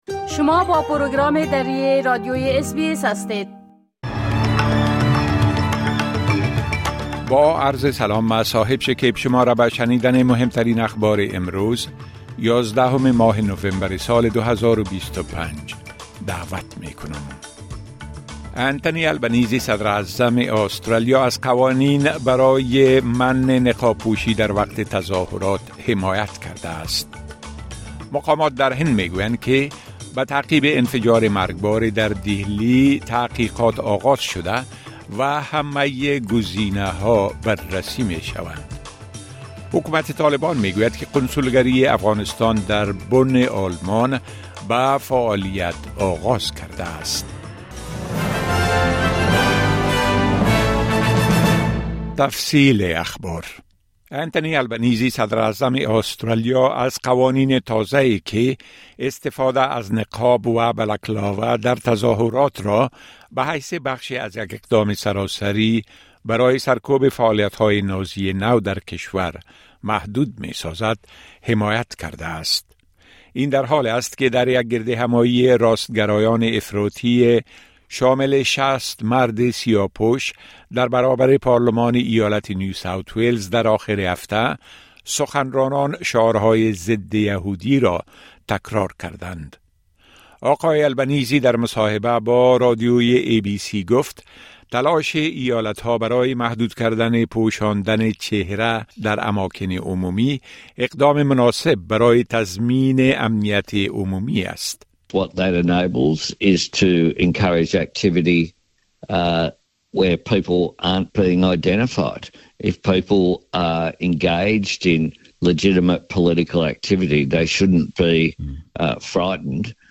مهمترين اخبار روز از بخش درى راديوى اس بى اس